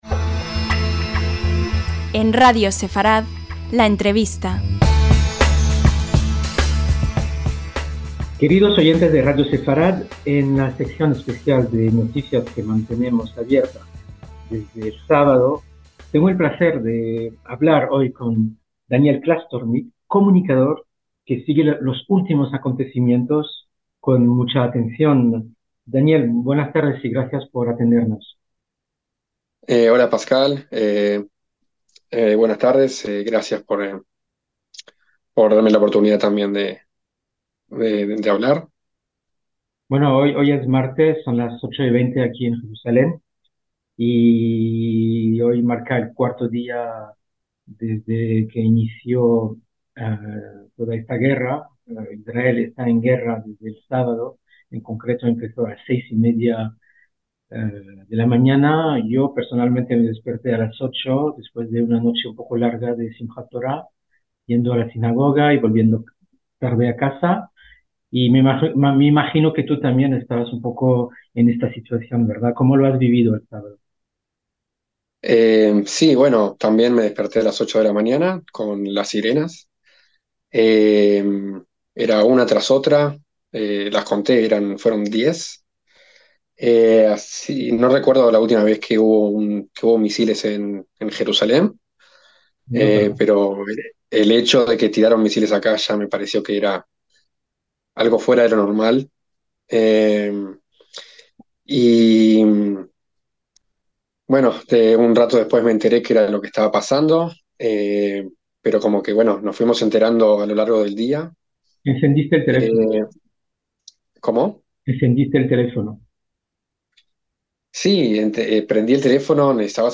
LA ENTREVISTA